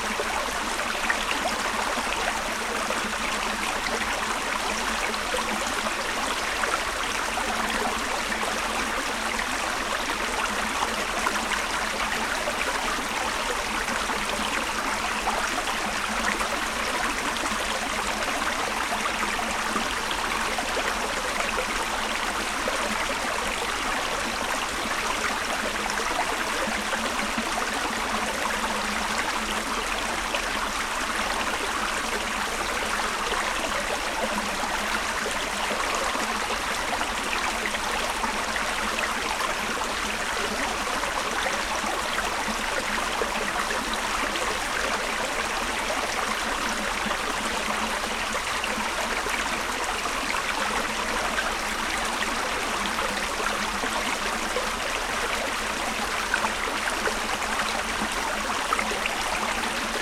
river1.ogg